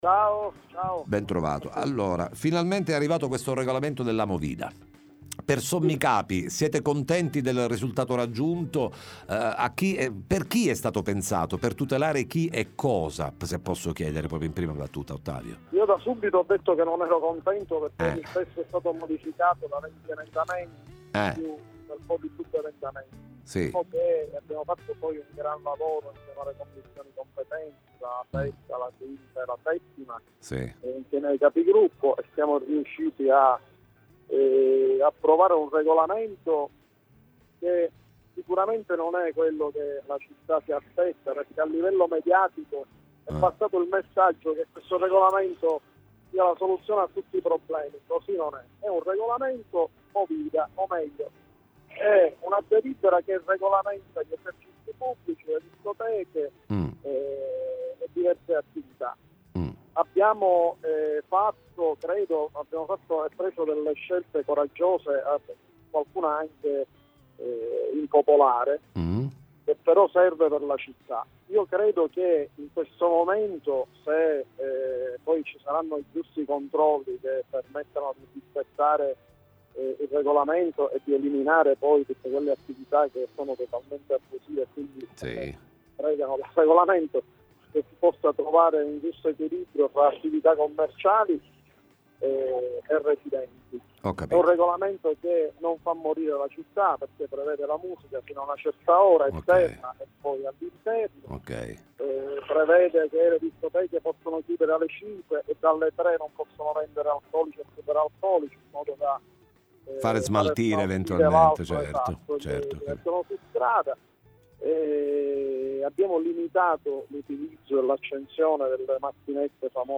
Movida e provvedimenti, ne parliamo con Ottavio Zacco, cons. com.